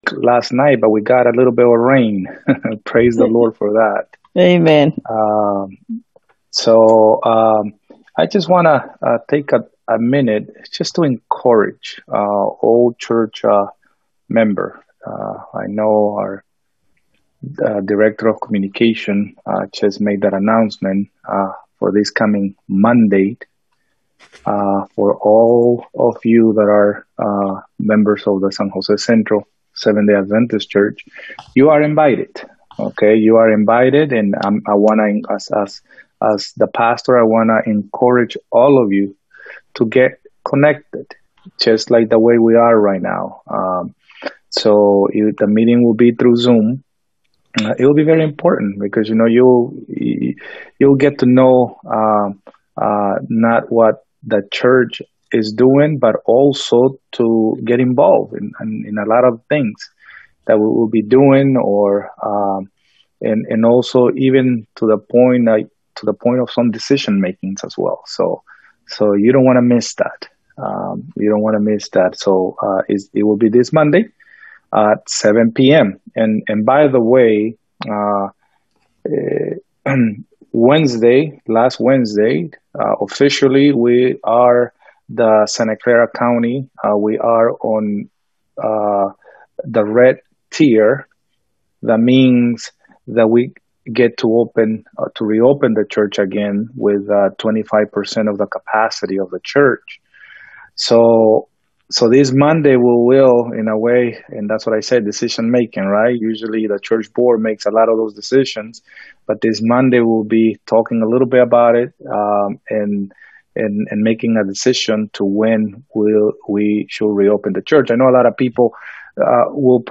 Seventh-day Adventist Church